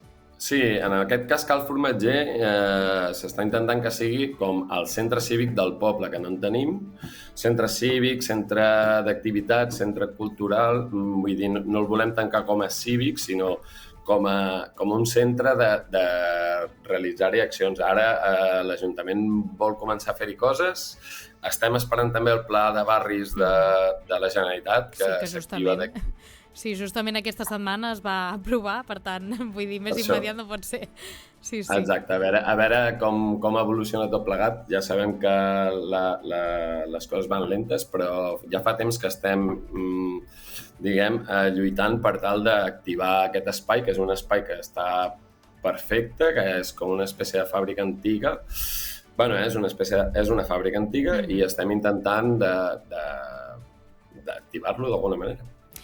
En una entrevista al programa Supermatí